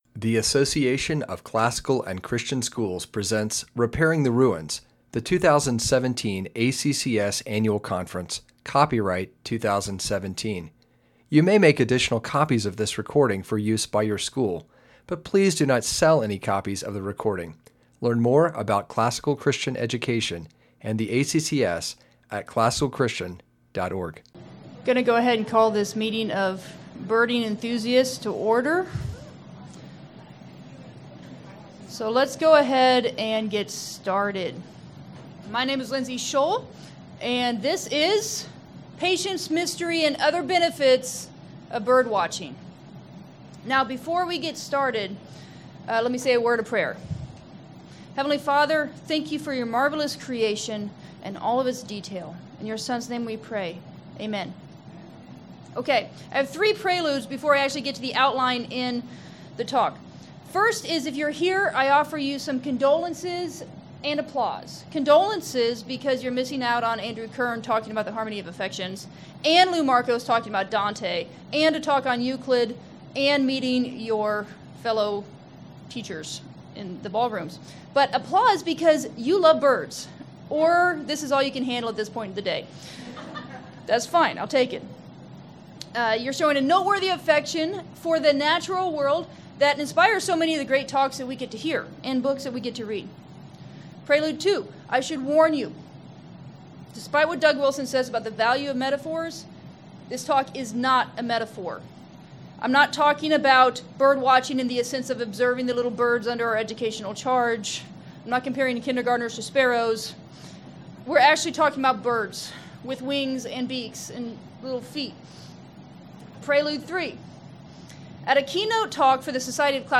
2017 Workshop Talk | 0:47:40 | All Grade Levels, Science
Speaker Additional Materials The Association of Classical & Christian Schools presents Repairing the Ruins, the ACCS annual conference, copyright ACCS.